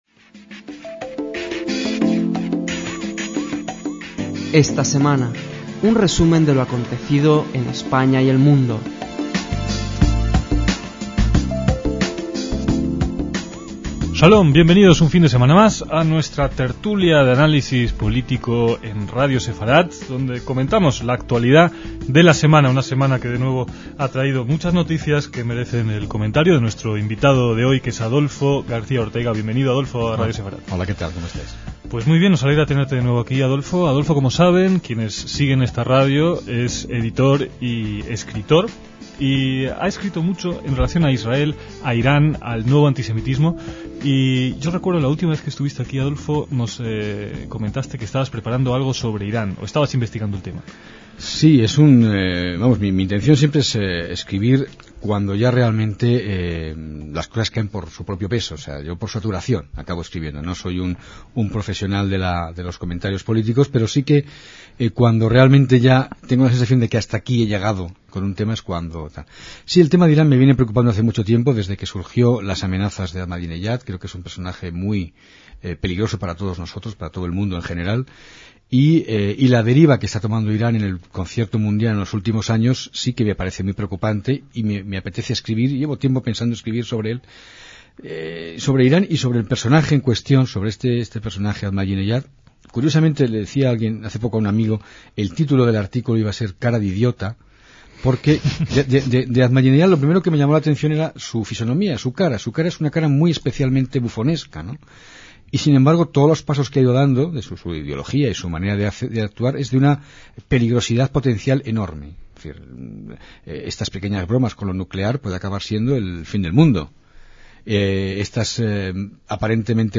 DECÍAMOS AYER (23/12/2006) - Esta tertulia semanal fue especial por ser casi la última de 2006 y porque tuvo dos presentadores y un único invitado, Adolfo García Ortega, para abordar diferentes temas de actualidad.